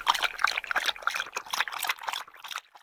petdrink.ogg